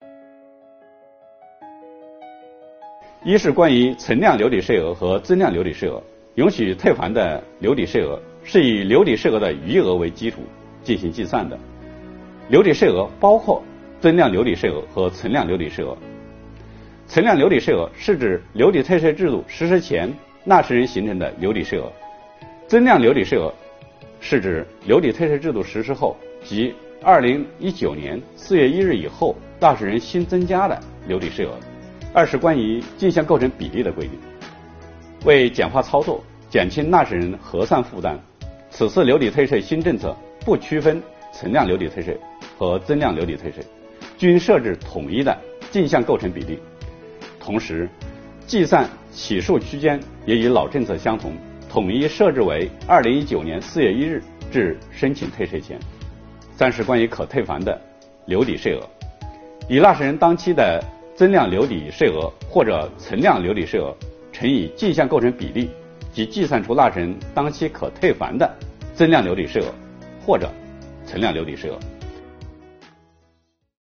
日前，新一期“税务讲堂”第五讲开讲。国家税务总局货物和劳务税司副司长刘运毛担任主讲人，详细解读了有关2022年大规模留抵退税政策的重点内容以及纳税人关心的热点问题。